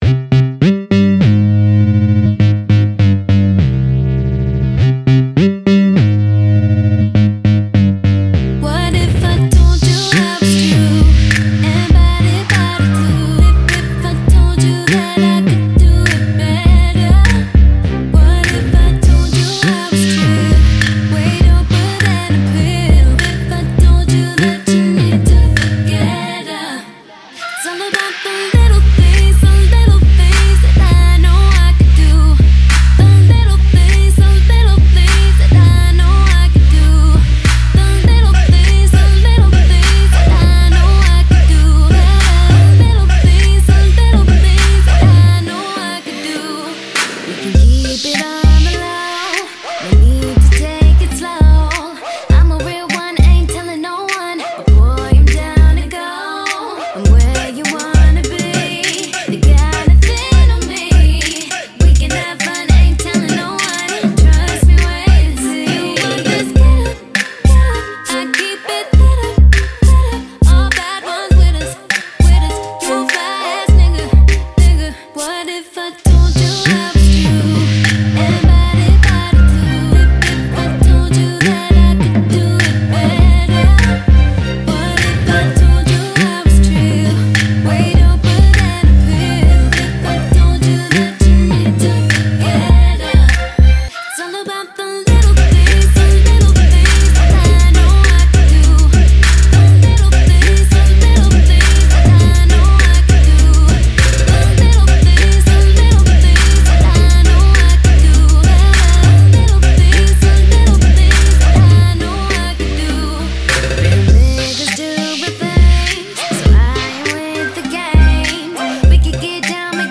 fun little party song to bob your head to